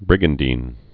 (brĭgən-dēn, -dīn)